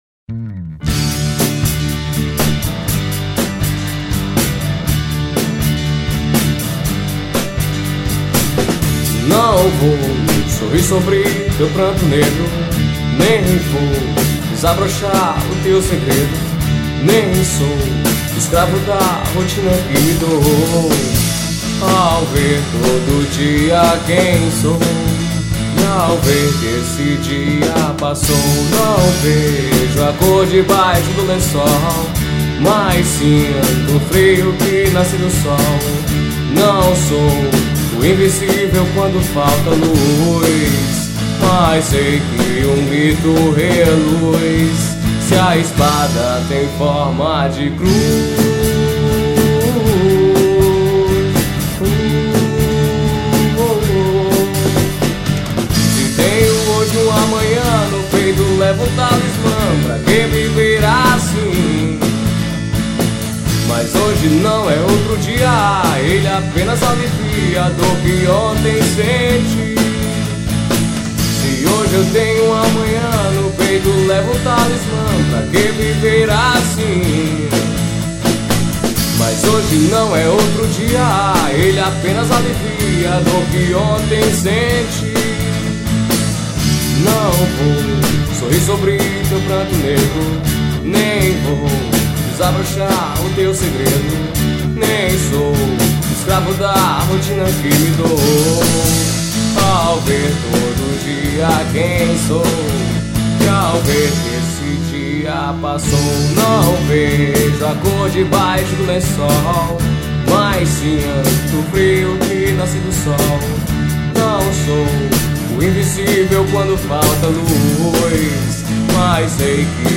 Rock Nacional